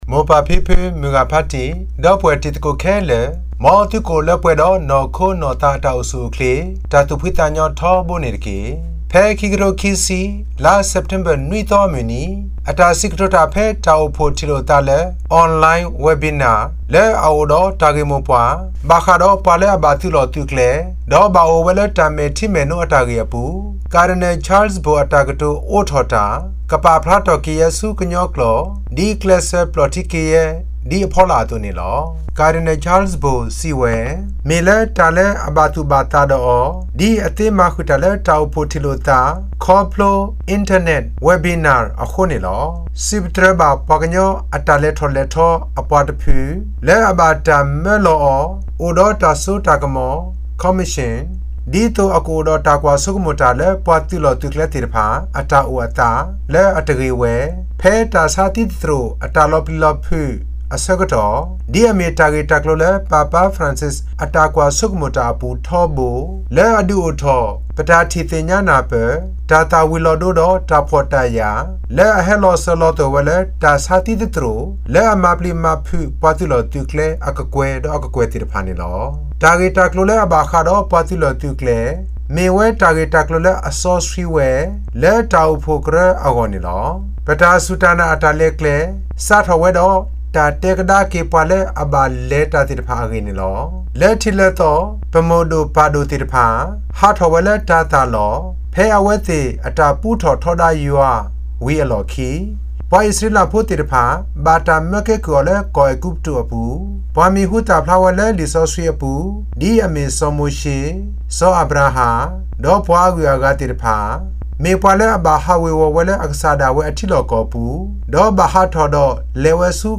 sep_7_cardinal_webinar_opening_talk.mp3